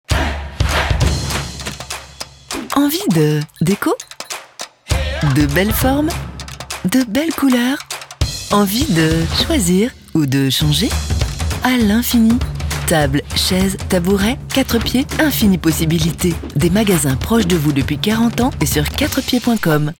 Voix Off Féminine
Type de voix : Souriante, Dynamique